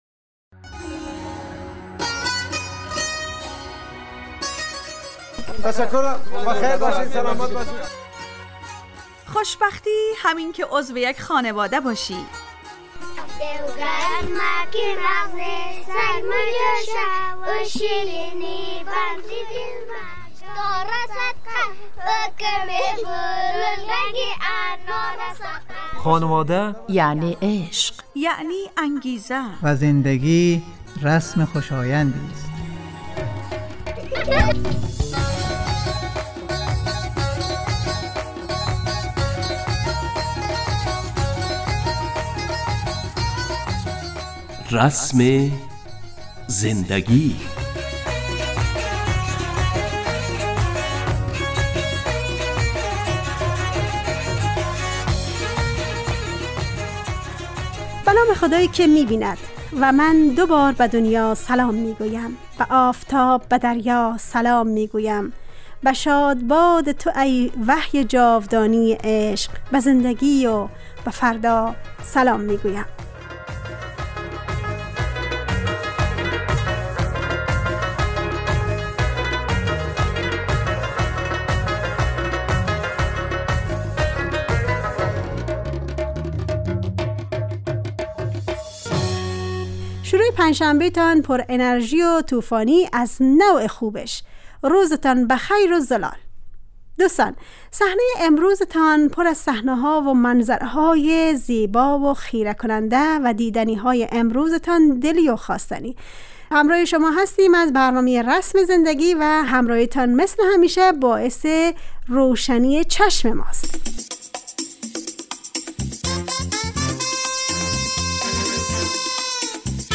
برنامه خانواده رادیو دری